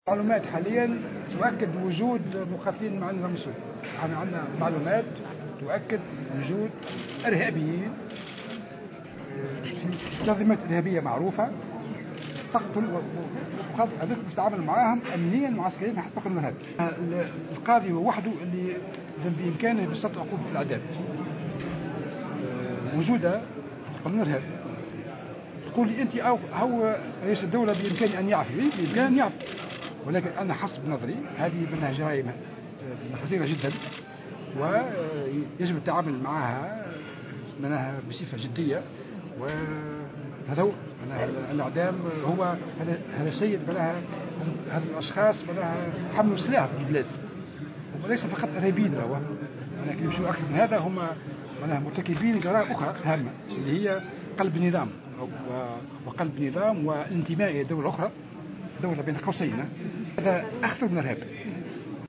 Dans une déclaration accordée au médias en marge d’un atelier de travail tenu sur le retour des terroristes des zones de conflit, Horchani a ajouté que ces derniers devront répondre d'accusations allant de tenter de renverser le régime, jusqu'à l'appartenance à un autre "pays".Ils seront jugés conformément à la loi anti-terroriste, a-t-il expliqué soulignant que certains chefs d'accusation sont passibles de la peine de mort.